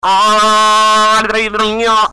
The sound was originally a random vocal utterance recorded sometime in my bedroom:
Vocal Chaos Original.mp3